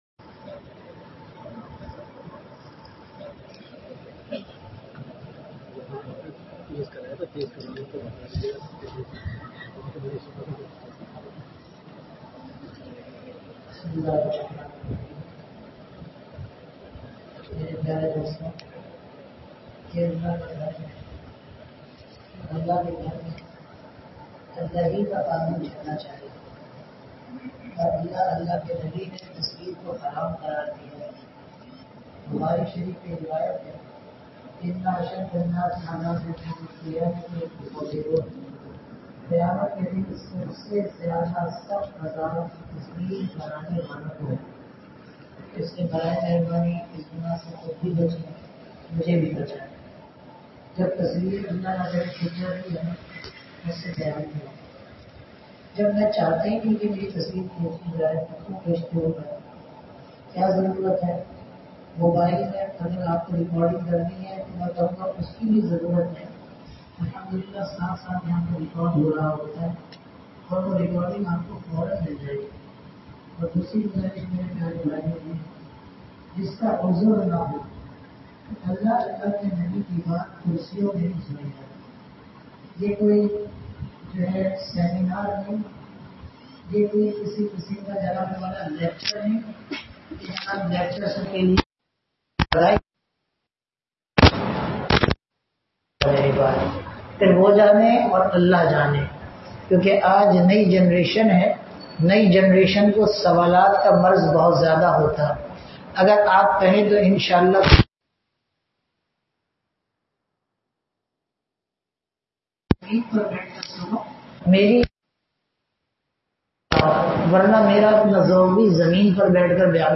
Bayanat
Apne bachon ko ilm e deen sikhaen (Rat 12:00 bje monwwra masjid block L northnazimabad